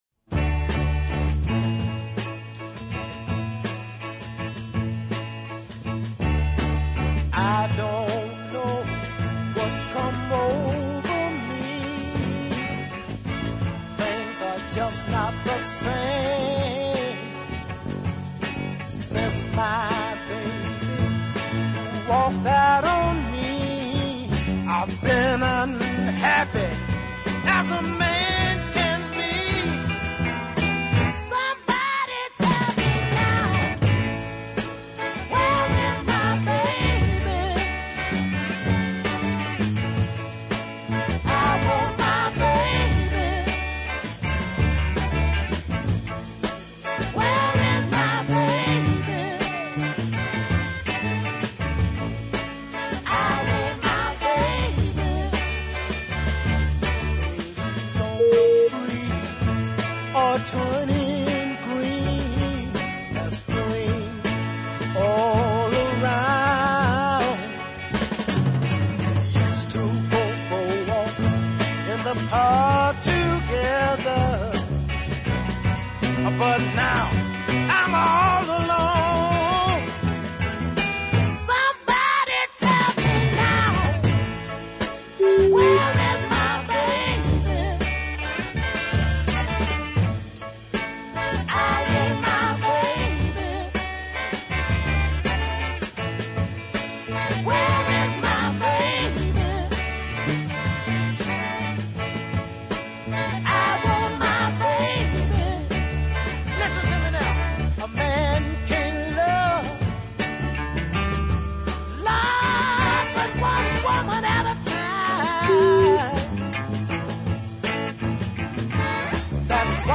The similarly paced